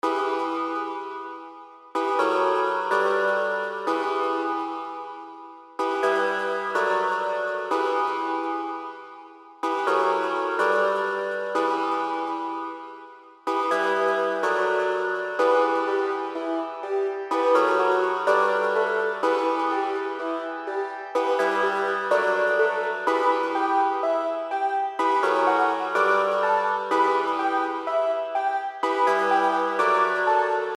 Orchastra[125 BPM]